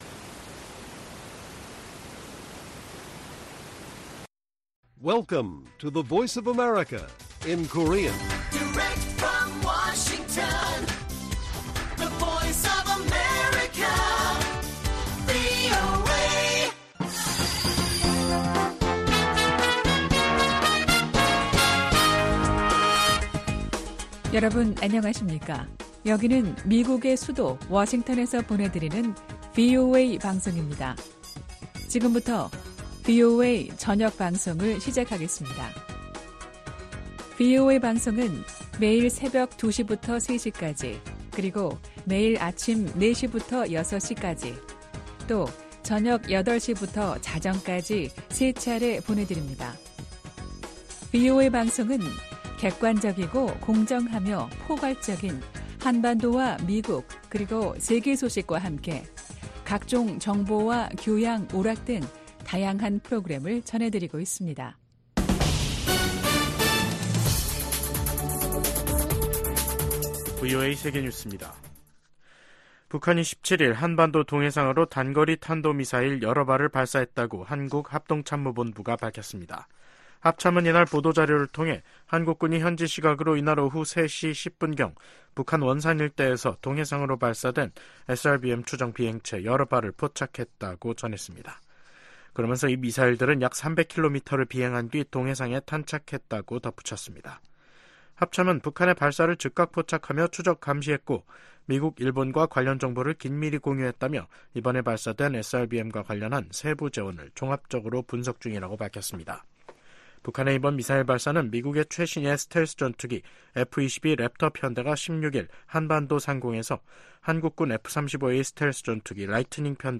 VOA 한국어 간판 뉴스 프로그램 '뉴스 투데이', 2024년 5월 17일 1부 방송입니다. 북한이 오늘 동해상으로 단거리 미사일 여러 발을 발사했습니다.